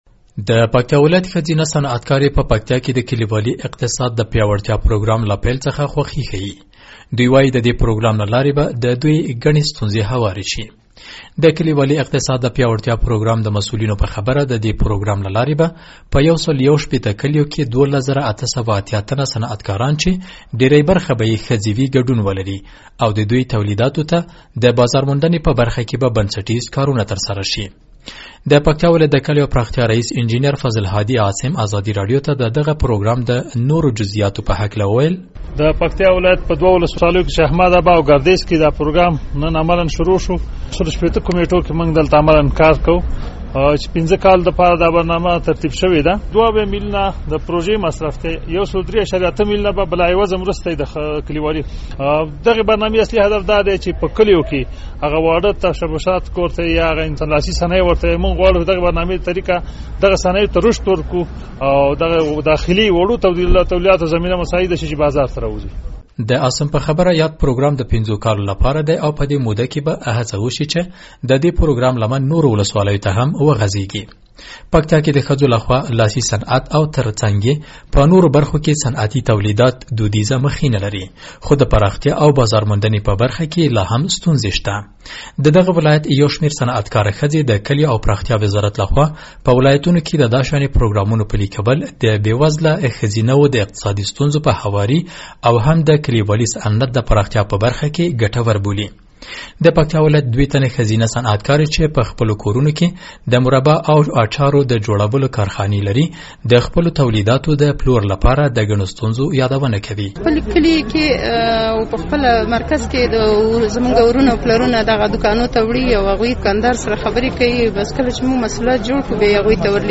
د پکتیا راپور